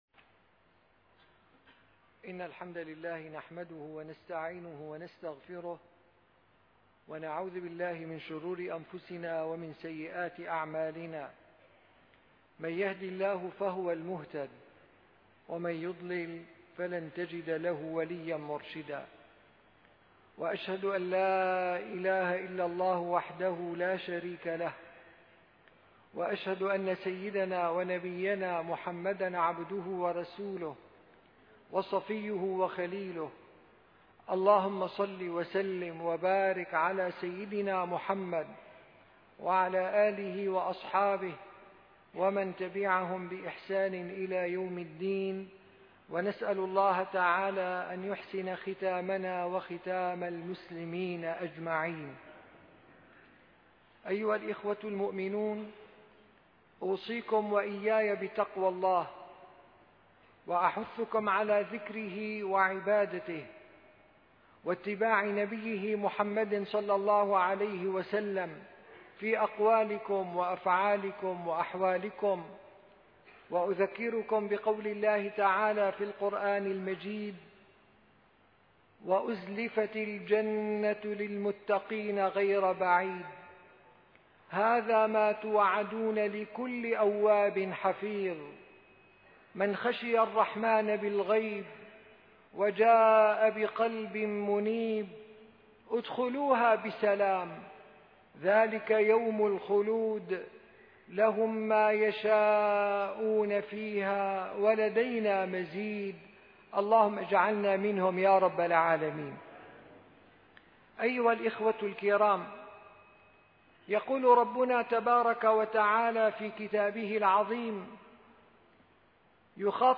- الخطب - الوحدة الوطنية سر انتصار الأمة